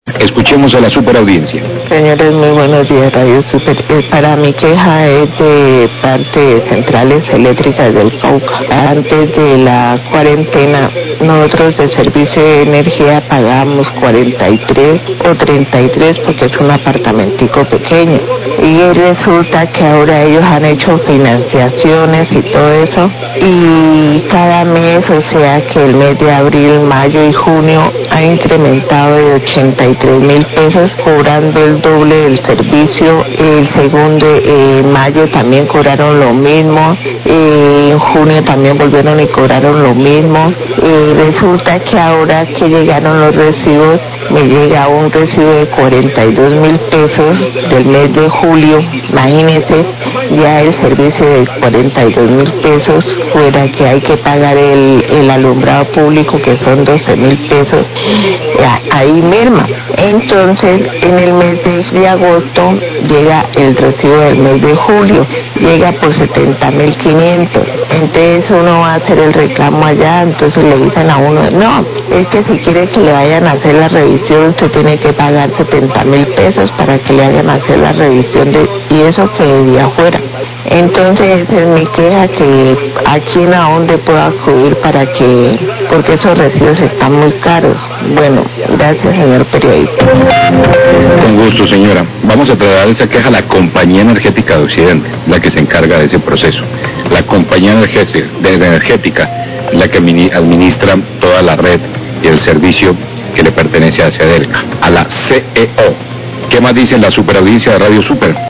Radio
Oyente emite una queja contra la Compañía Energética por los cobros muy altos que le llegan ahora en la actura de energhía, antes de la cuarentena le llegaban más baratos. Agrega que presentó el reclamo pero le respondieron que pagar por la revisión.